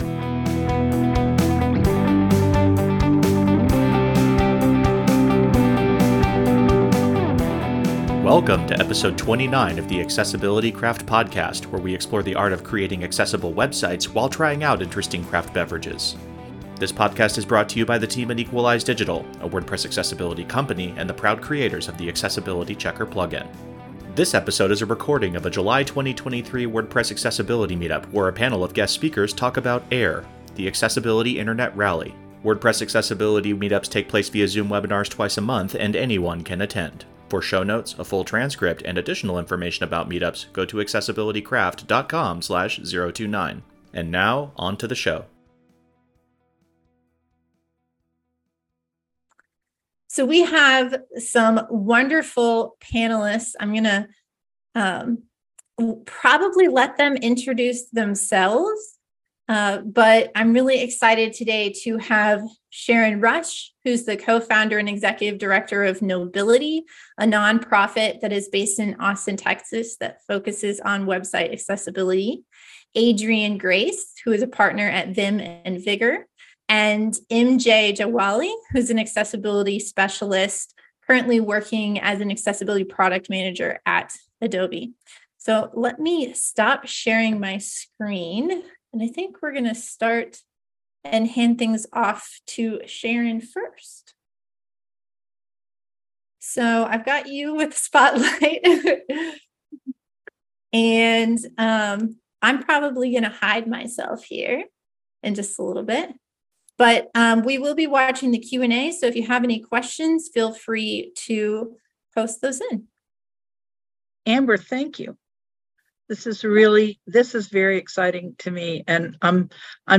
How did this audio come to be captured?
This episode is a recording of a July 2023 WordPress Accessibility Meetup where a panel of guest speakers talk about AIR -- the Accessibility Internet Rally. WordPress Accessibility Meetups take place via Zoom webinars twice a month, and anyone can attend.